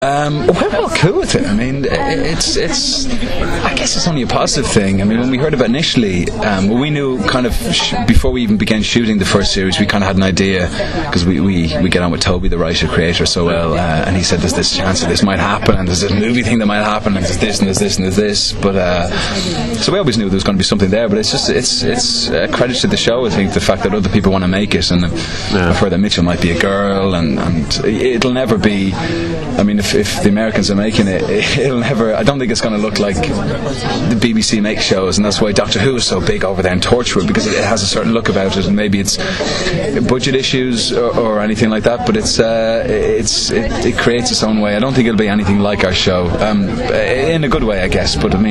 Now here are a few extracts, with audio, from the interviews with Aidan and Lenora, who is also currently starring as fashion designer Ali in BBC1 drama series Material Girl: